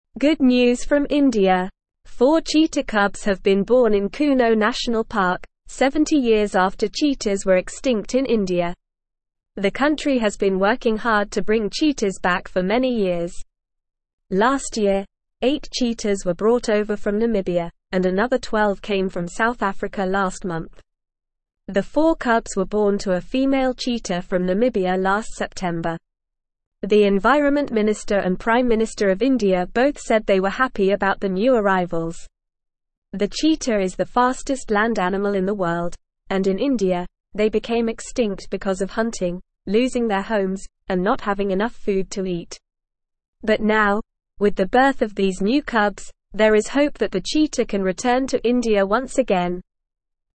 Normal
English-Newsroom-Beginner-NORMAL-Reading-India-Has-Four-New-Baby-Cheetahs.mp3